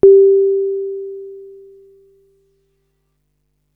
clean signal from a YM2164 (from a FB01 unit).
YM2164clean.wav